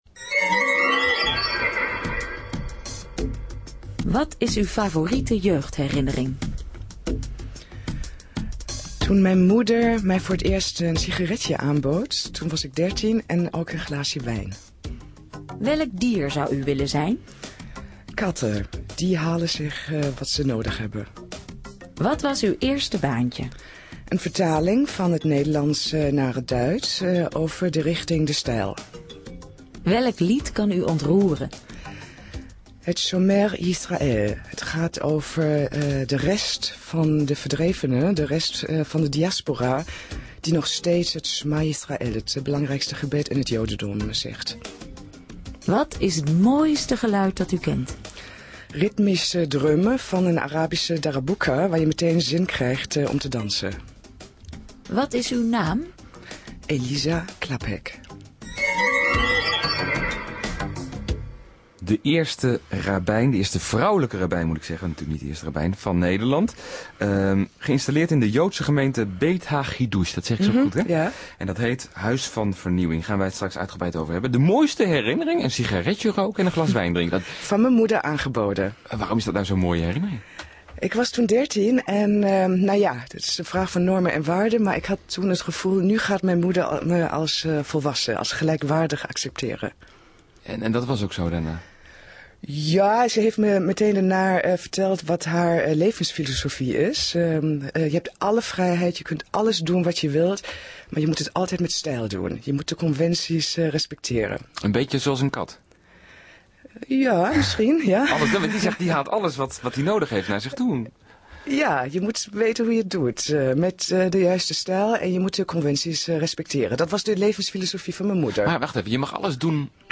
Interview Radio747